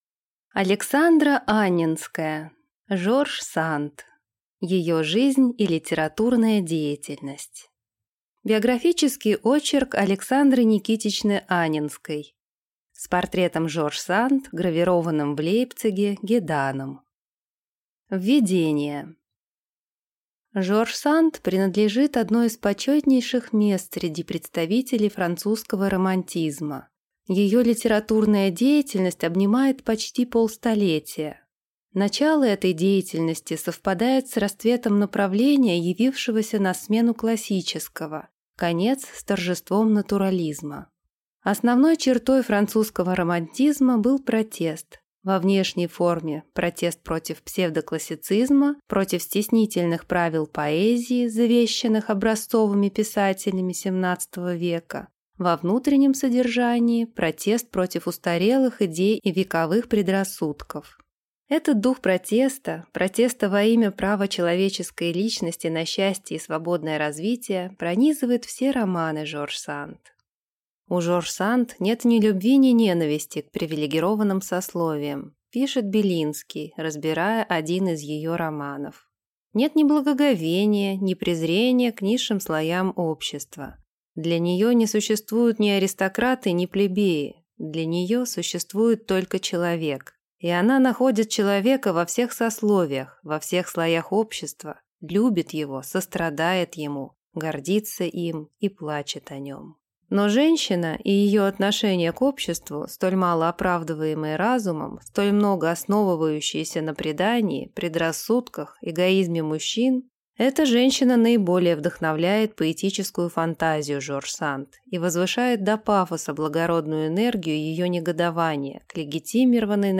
Аудиокнига Жорж Санд. Ее жизнь и литературная деятельность | Библиотека аудиокниг